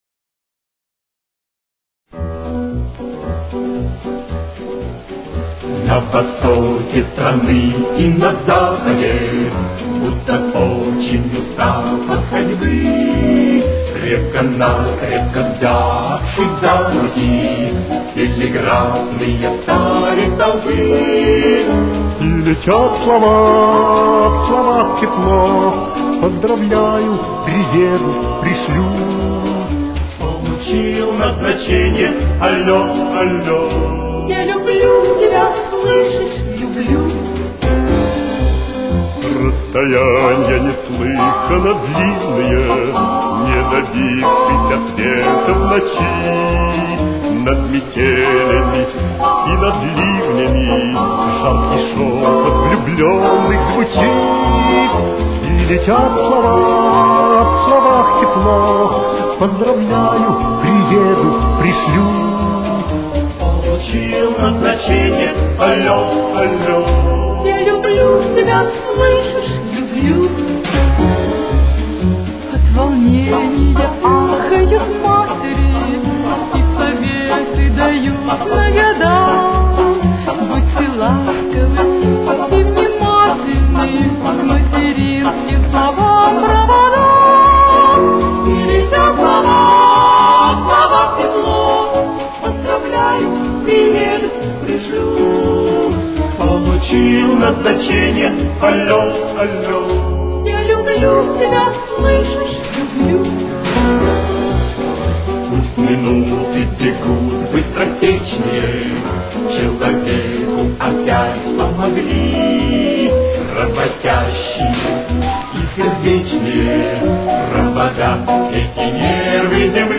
Тональность: Ми минор. Темп: 121.